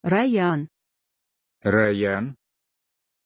Aussprache von Rayyan
pronunciation-rayyan-ru.mp3